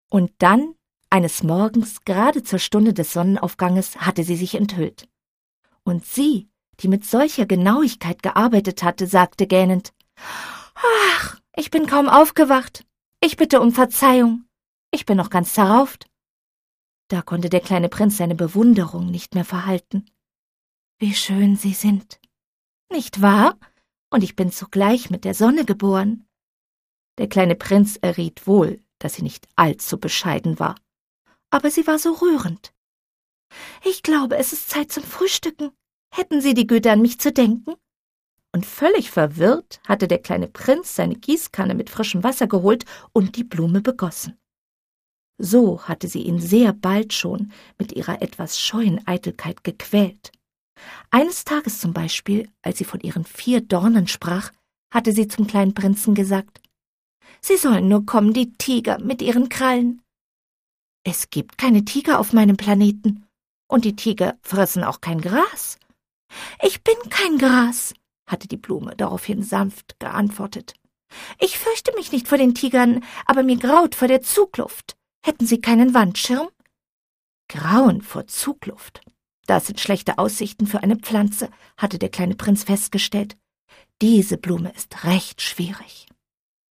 Eine Lesung mit musikalischer Untermalung